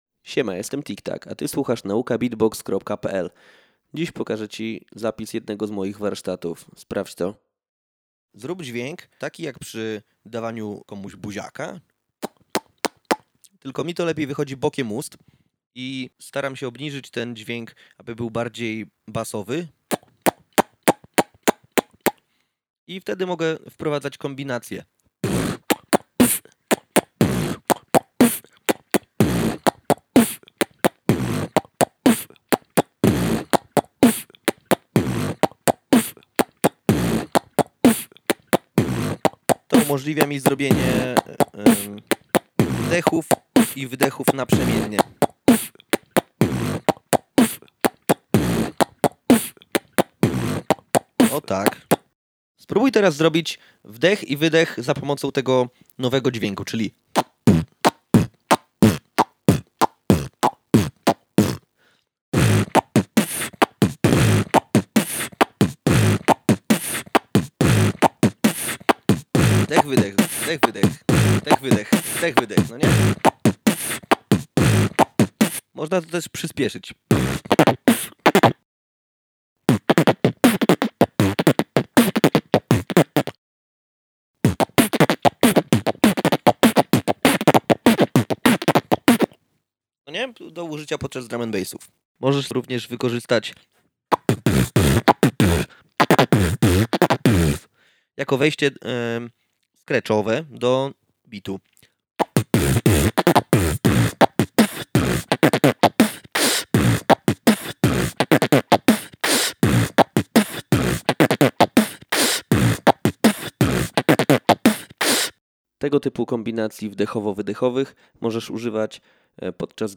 Cmokanie na wdechu i wydechu
Sprawdź szybki fragment nagrany podczas jednego z moich warsztatów. Dotyczy cmokania na wdechu i wydechu, w połączeniu z bitem.
Bity loopowałem używając TC Helicon Voice Live.